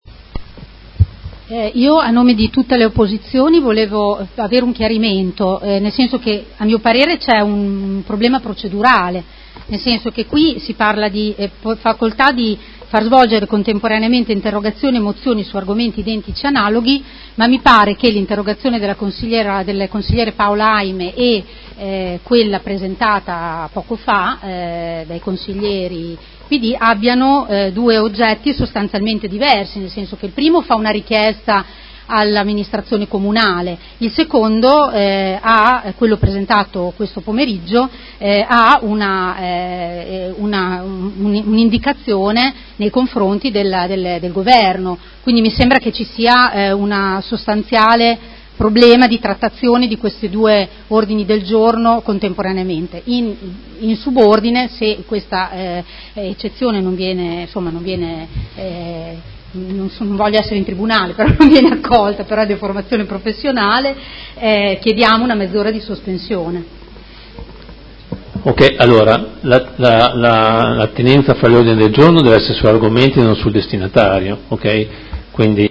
Elisa Rossini — Sito Audio Consiglio Comunale
Seduta del 25/07/2019 Chiede una sospensione.